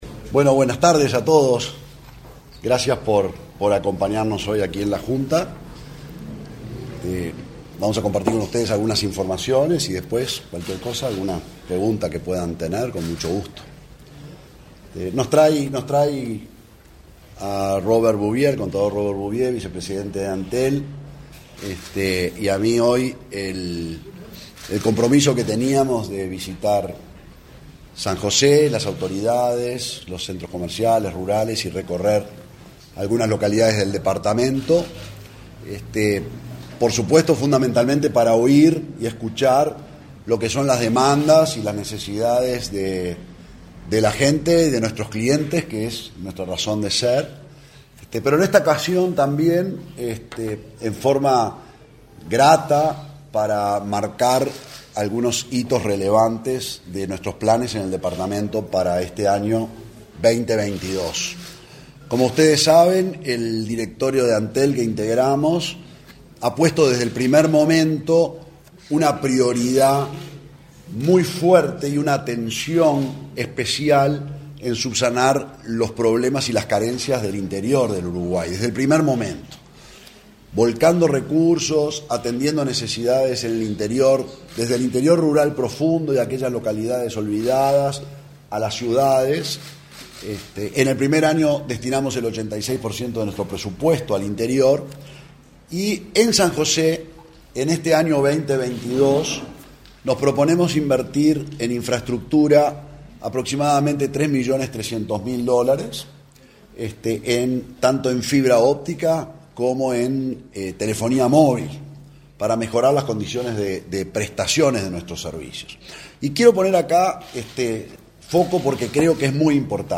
Conferencia de prensa de autoridades de Antel
También participó el vicepresidente de la empresa, Robert Bouvier.